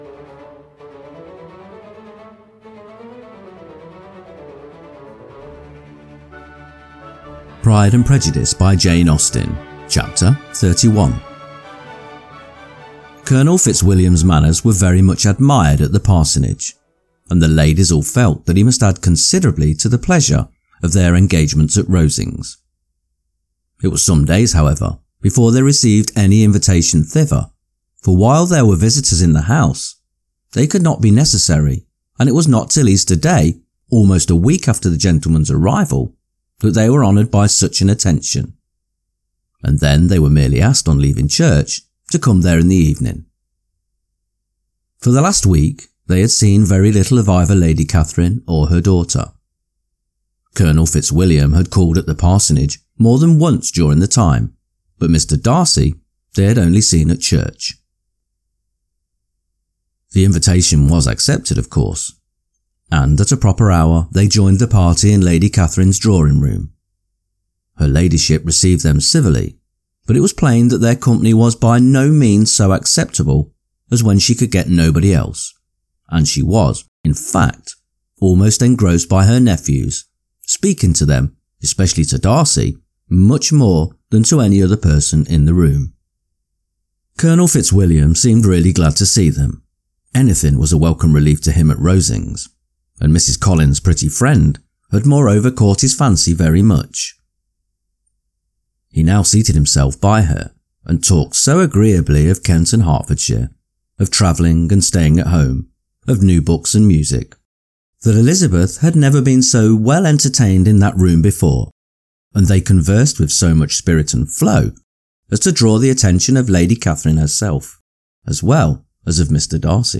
Pride and Prejudice – Jane Austen Chapter 31 Narrated - Dynamic Daydreaming